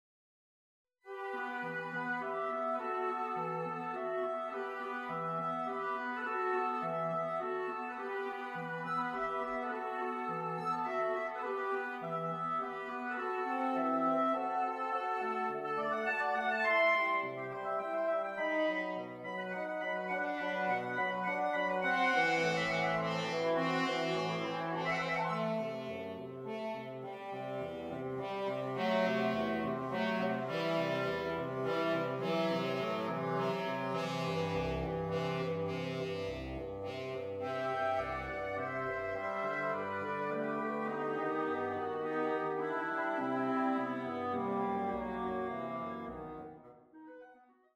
piano suite
tender innocence, nobility, and emotional depth
melancholy and grace